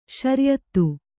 ♪ śaryattu